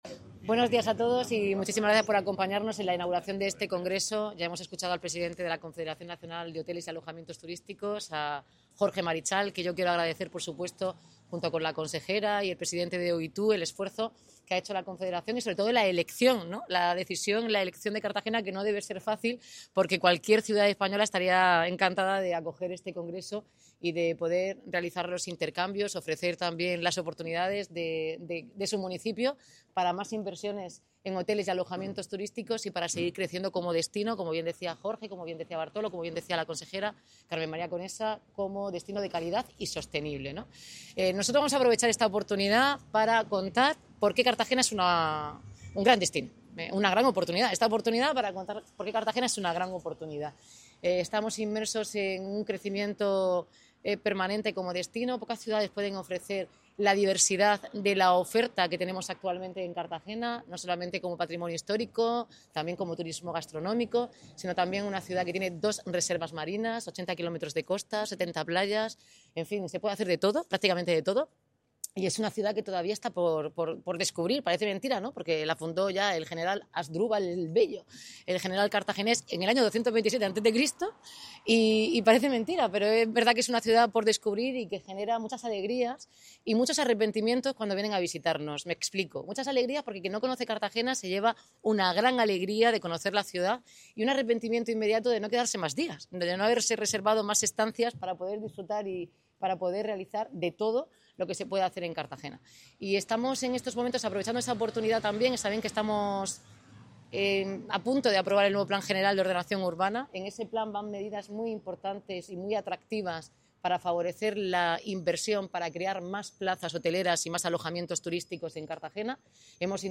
Enlace a Declaraciones de la alcaldesa Noelia Arroyo.
Cartagena se consolida como punto estratégico en el mapa turístico nacional, con la celebración estos días del XX Congreso de Hoteleros Españoles, encuentro de gran relevancia para el sector, que ha congregado en el Auditorio y Palacio de Congresos El Batel a los principales líderes y profesionales de la hostelería nacional, y que se celebra bajo el lema 'Cuidamos el destino'.